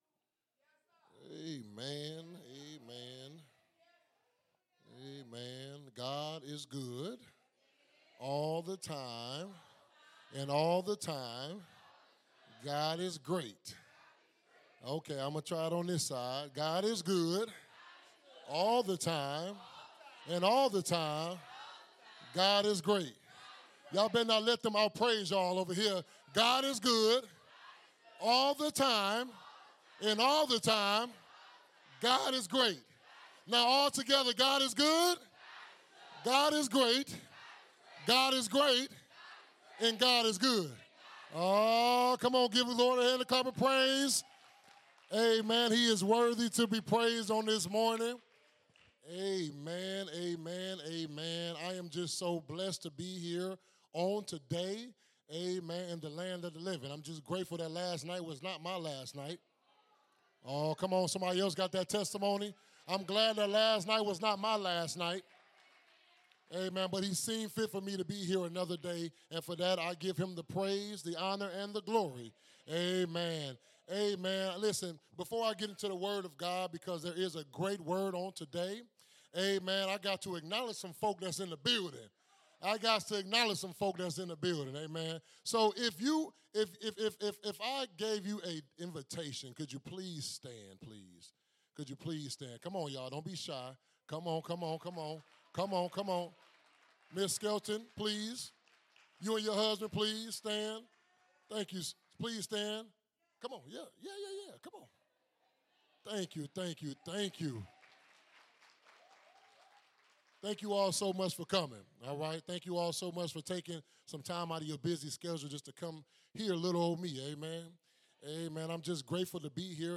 Sunday Morning Service 1-18-26 (While you were sleeping! God is still working )